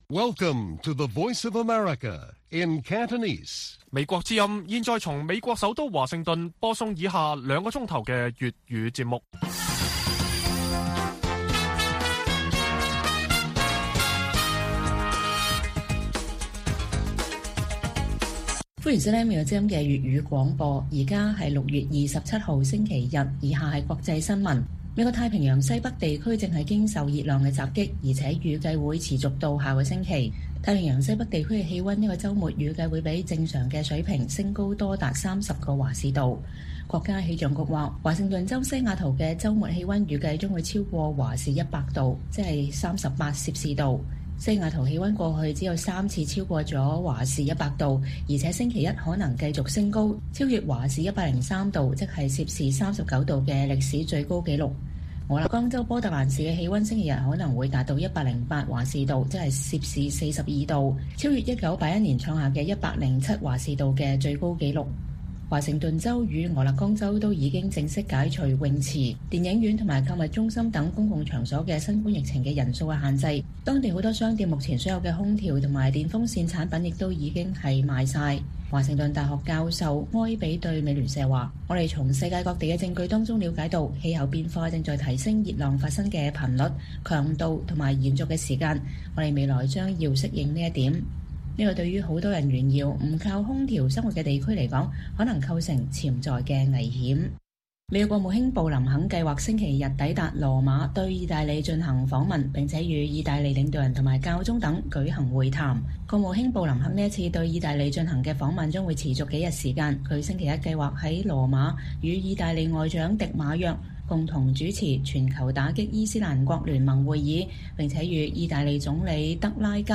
粵語新聞 晚上9-10點：美國太平洋西北地區正在經受熱浪襲擊